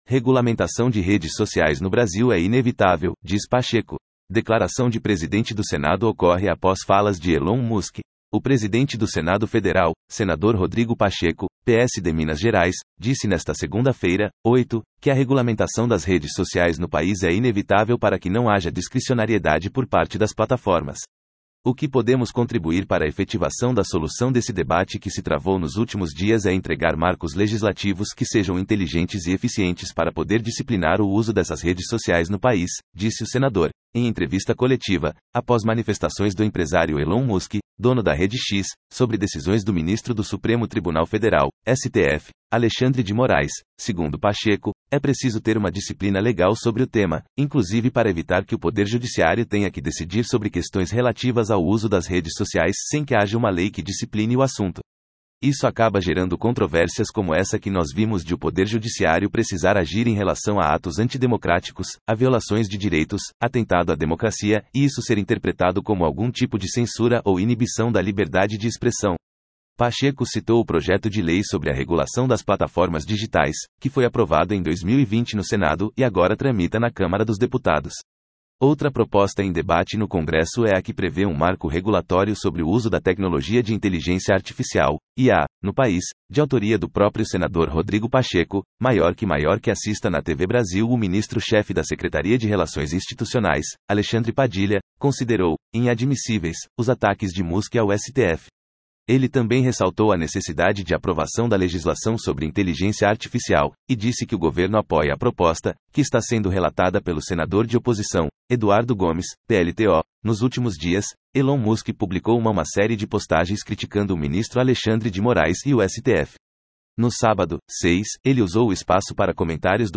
“O que podemos contribuir para efetivação da solução desse debate que se travou nos últimos dias é entregar marcos legislativos que sejam inteligentes e eficientes para poder disciplinar o uso dessas redes sociais no país”, disse o senador, em entrevista coletiva, após manifestações do empresário Elon Musk, dono da rede X, sobre decisões do ministro do Supremo Tribunal Federal (STF), Alexandre de Moraes.